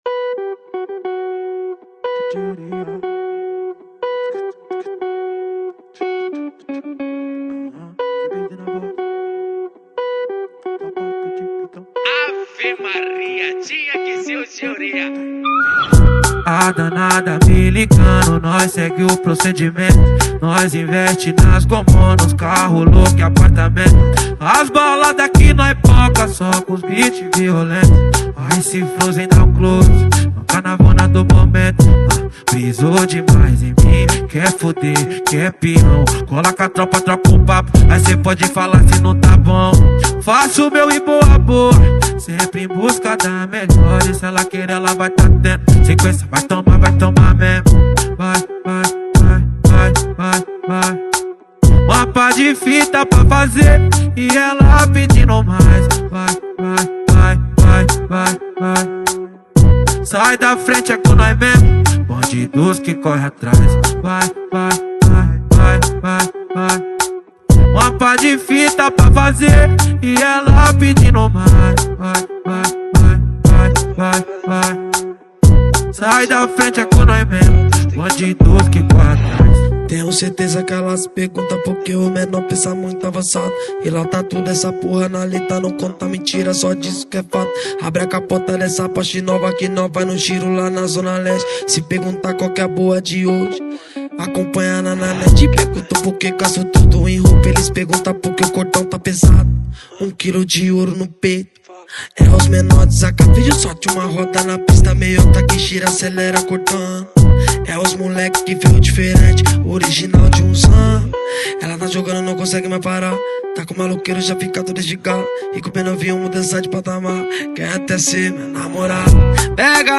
2024-09-27 10:11:00 Gênero: MPB Views